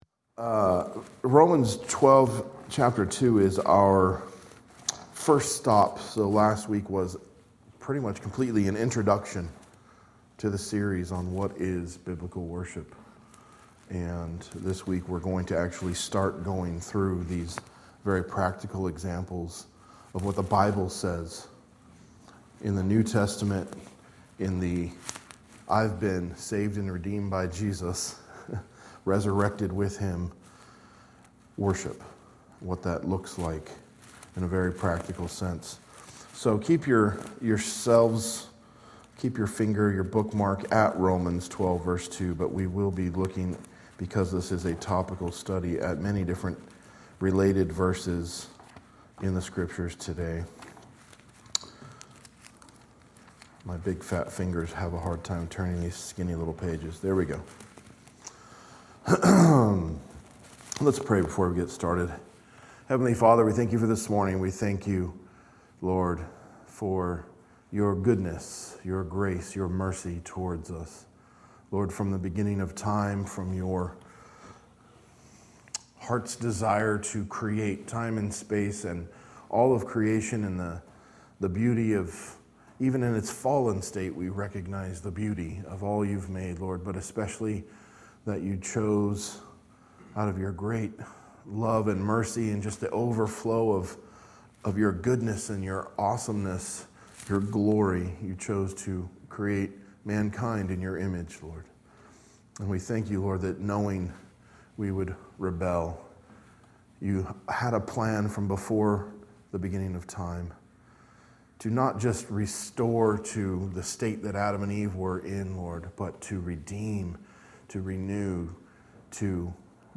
A message from the series "Biblical Worship Series."